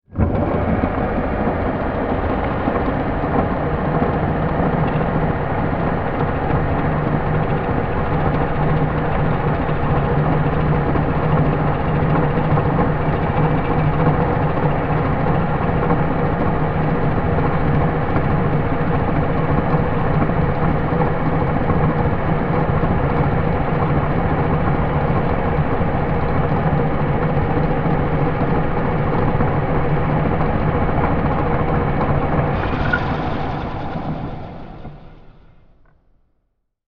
Шум подъемного устройства крана